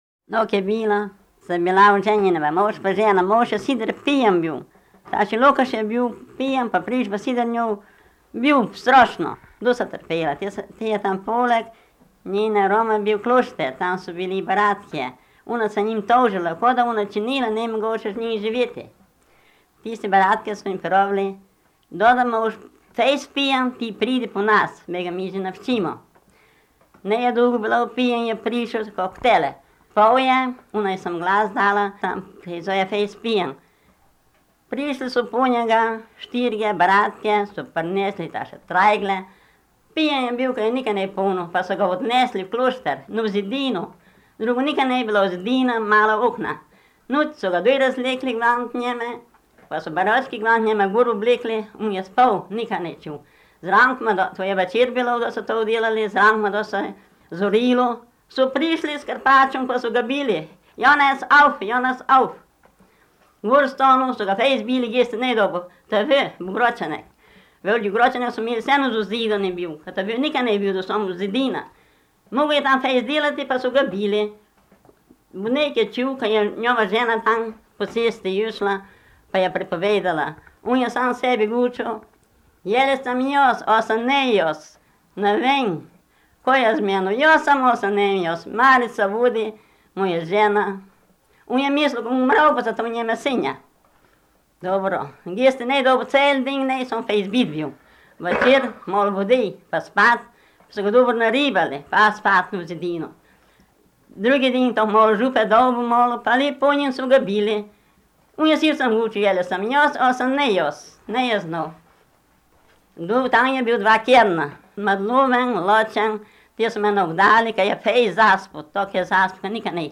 V knjigi so zbrane porabske pravljice in povedke, ki jih je leta 1970 posnel Milko Matičetov na magnetofonske trakove.
Dodana je zgoščenka s tonskimi posnetki trinajstih pravljic in povedk v obeh različicah porabskega narečja (števanovskem in gornjeseniškem).